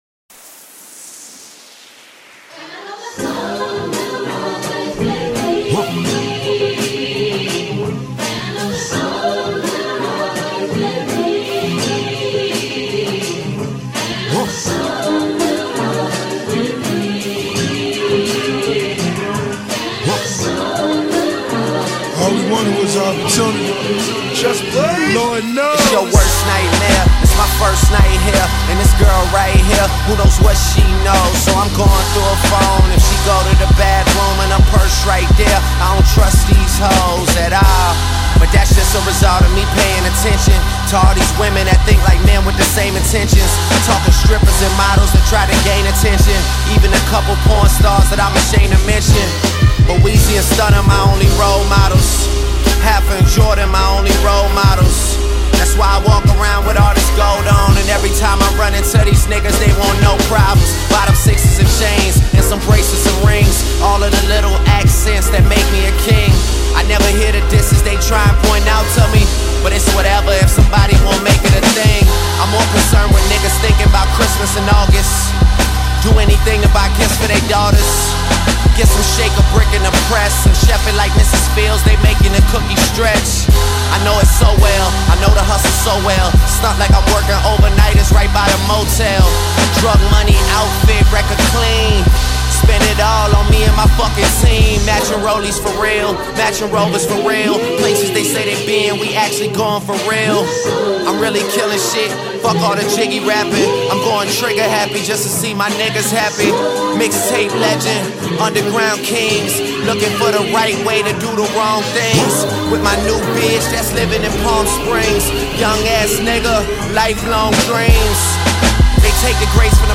begins with a gospel-tinged refrain